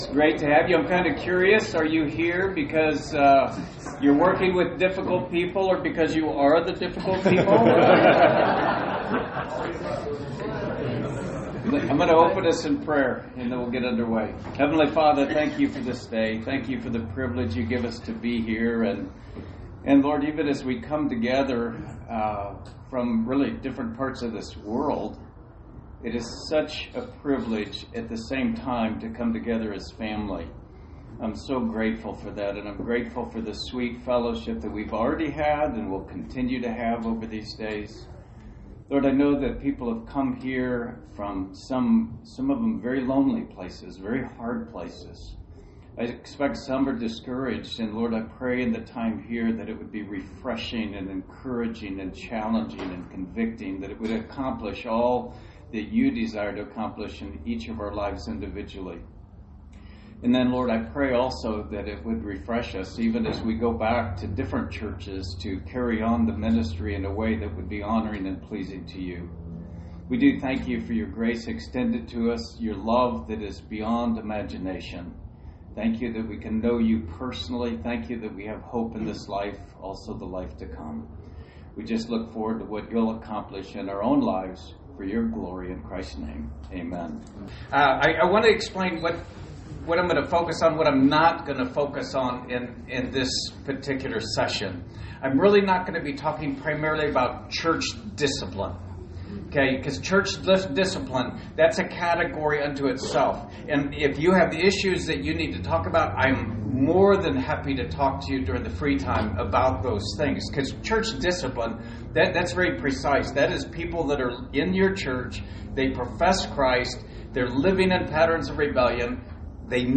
Series: Shepherds Conference 2025